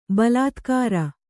balātkāra